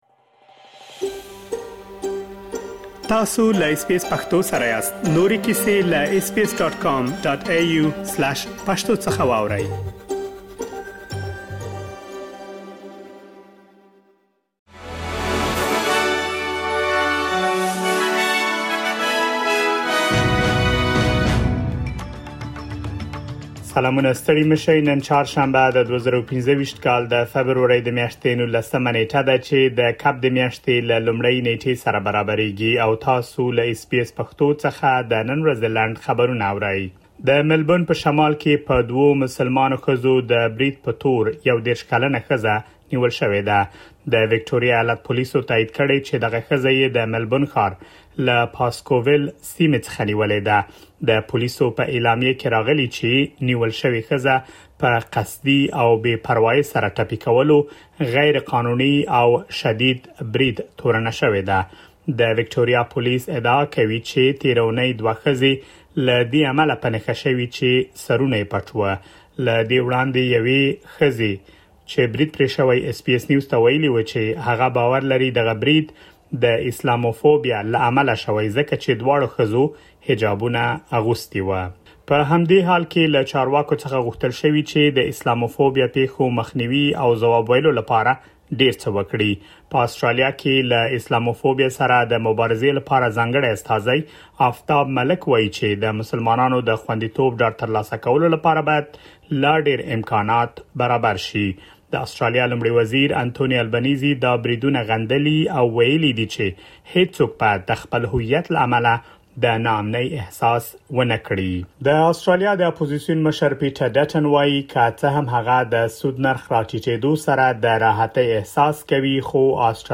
د اس بي اس پښتو د نن ورځې لنډ خبرونه | ۱۹ فبروري ۲۰۲۵